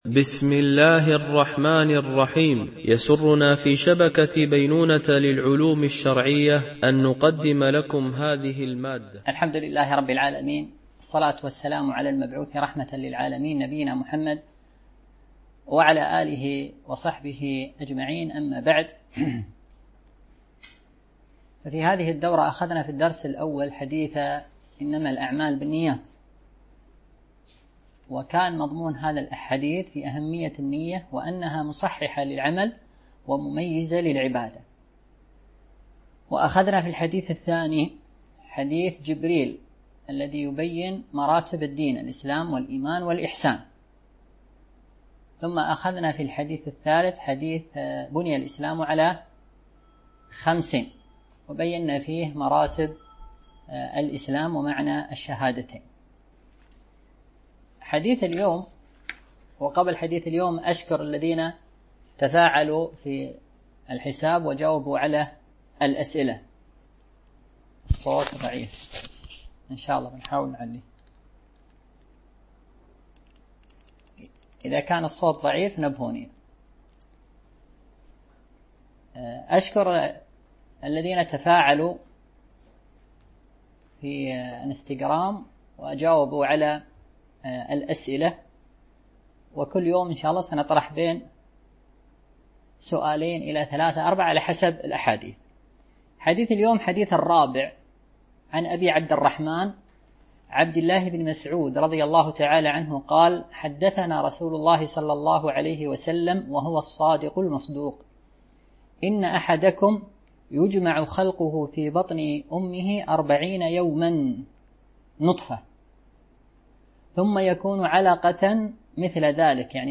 دورة علمية عن بعد